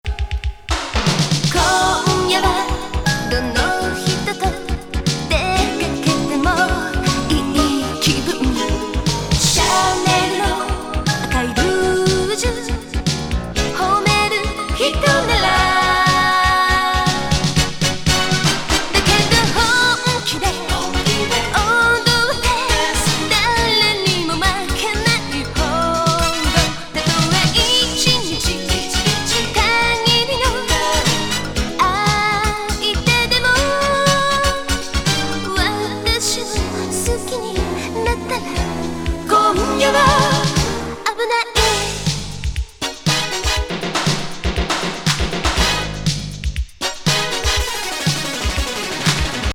モダン・ブギー